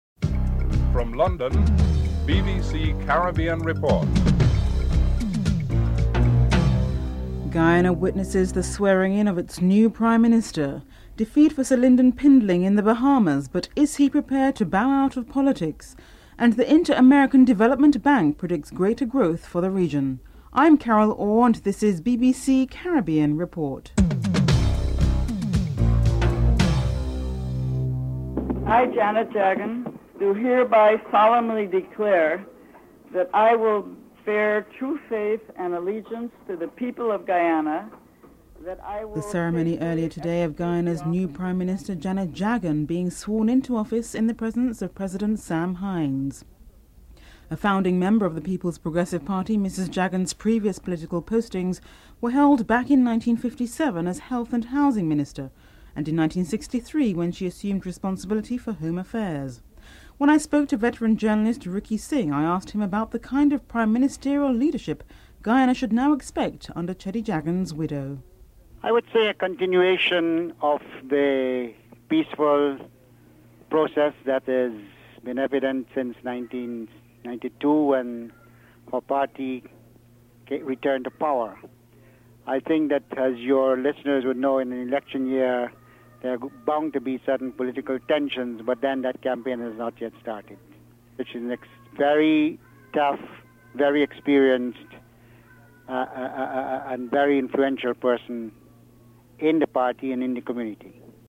1. Headlines (00:00-00:35)
Director of the Caribbean Development Bank, Sir Neville Nicholls is interviewed (10:10-12:29)